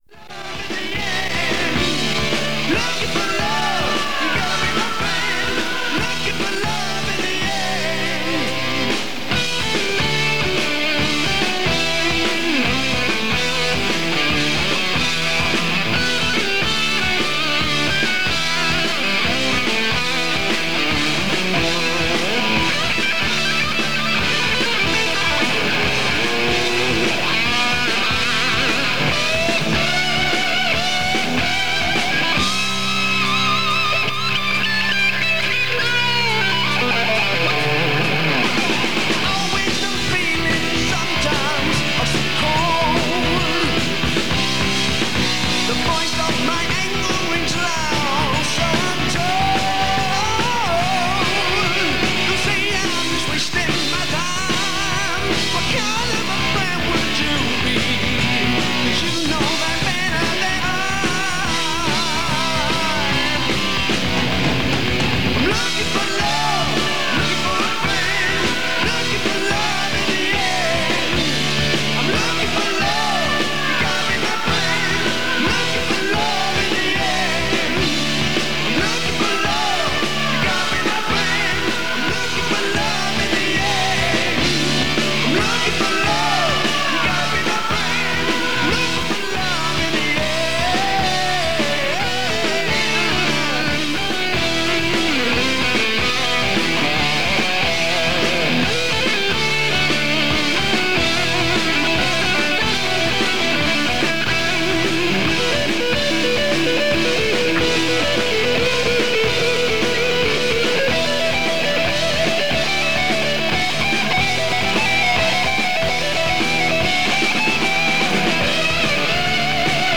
There are sung jingles in this recording but no adverts. Audio quality is fair with an unprocessed signal and some interference at times.